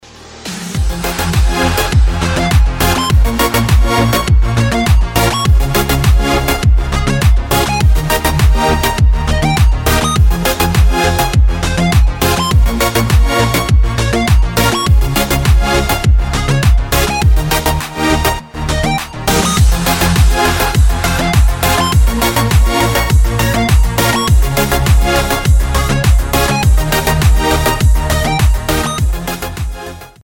• Качество: 256, Stereo
dance
club